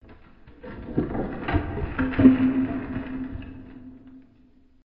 国内声音 " 木屋里的落木01混响
描述：落在木棚里的原木 用数字录音机录制并使用Audacity处理
标签： 崩溃 崩溃 跌落 碰撞 坠落 地震 霍霍 桩木 隆隆 隆隆 震颤 摇晃 倒塌 撞击 日志
声道立体声